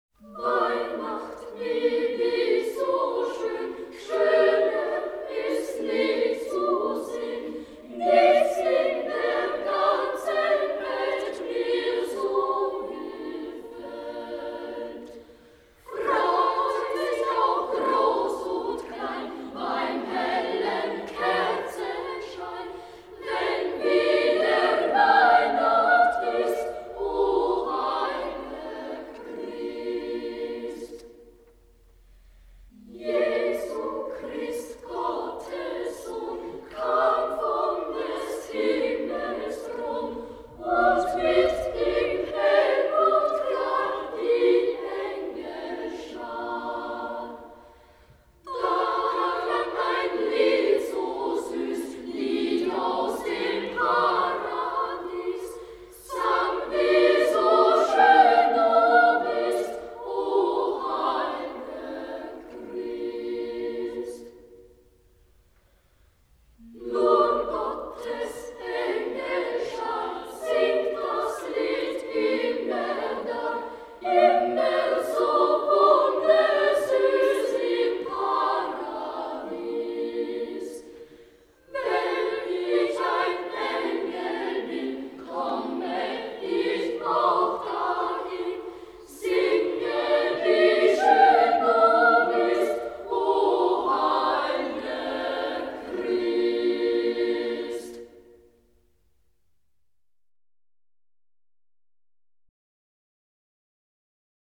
This Alpine carol was first written down in 1906.